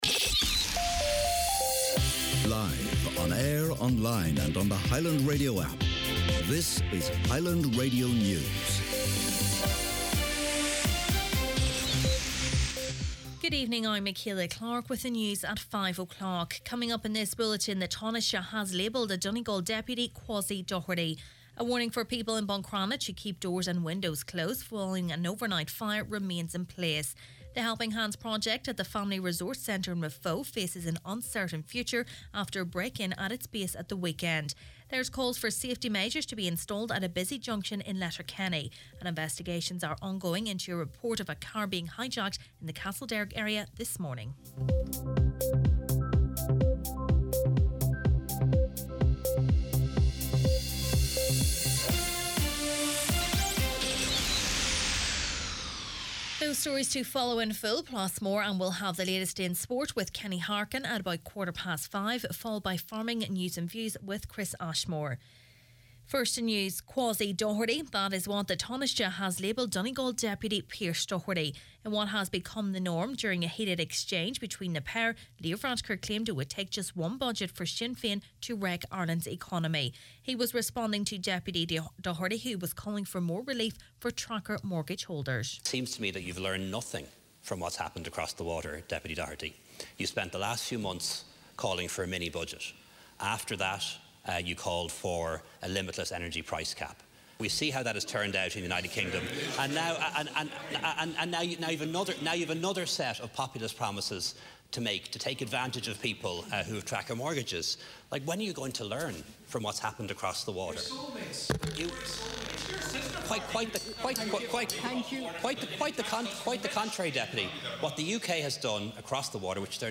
Main Evening News, Sport, Farming News and Obituaries – Thursday October 27th